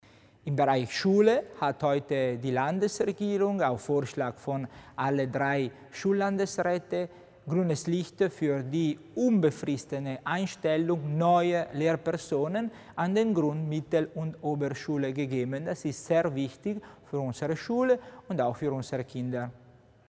Landesrat Tommasini erklärt die Neuheiten zur Schule